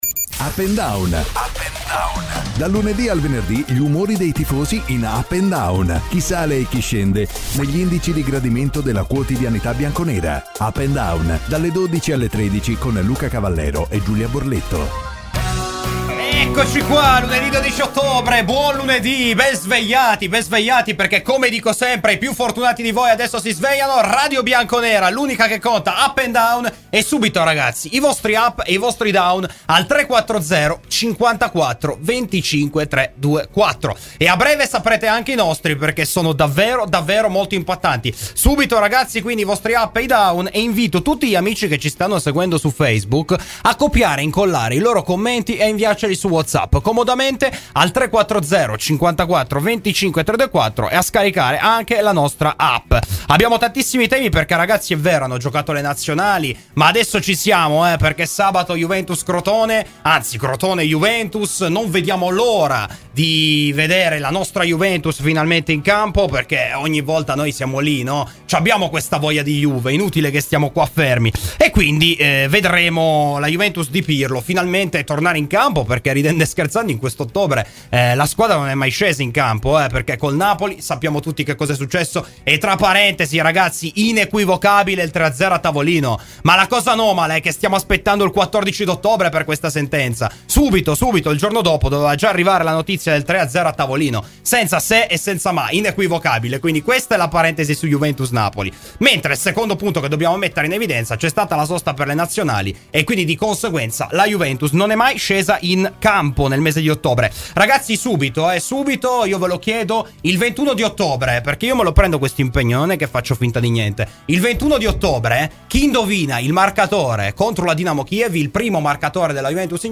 Salvatore Aronica, doppio ex di Juventus e Napoli, ha parlato a Radio Bianconera nel corso di ‘Up&Down’.